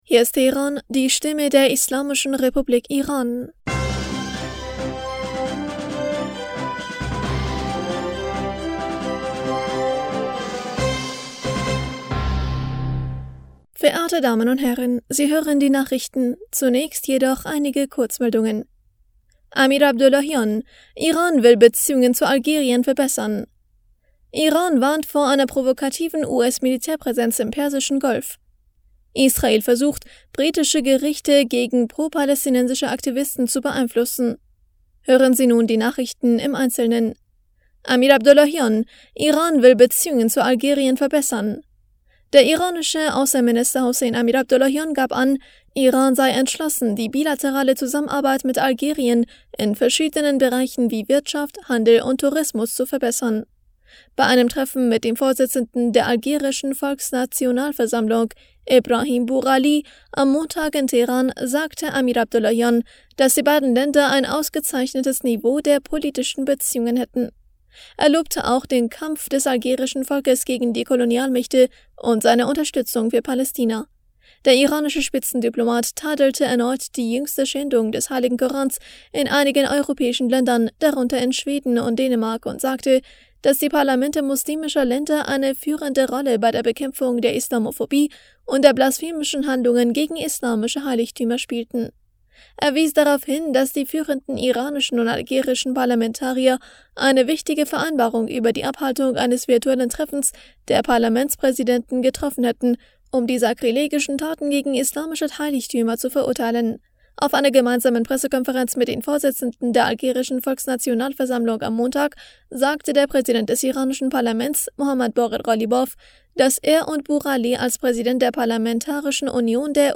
Nachrichten vom 22. August 2023